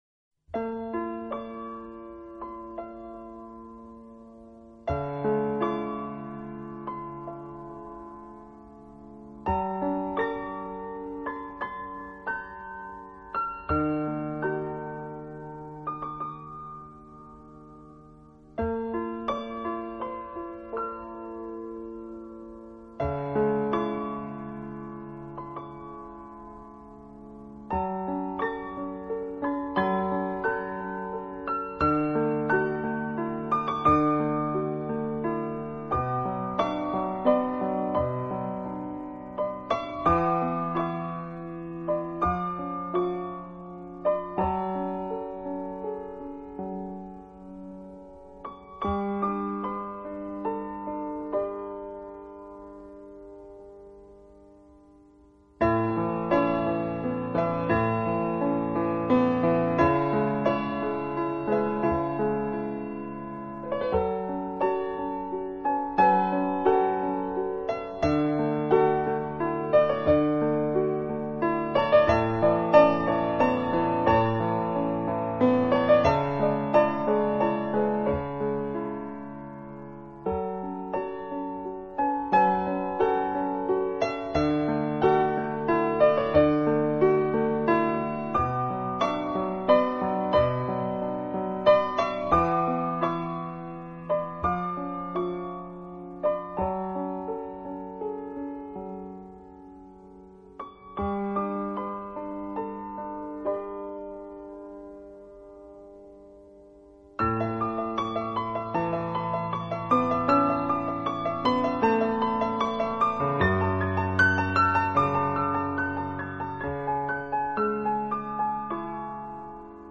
【新世纪钢琴】
诠释出夜幕低垂的昏黄时刻，低调而灰暗、幽远的情趣。